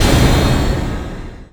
Magic_SpellImpact20.wav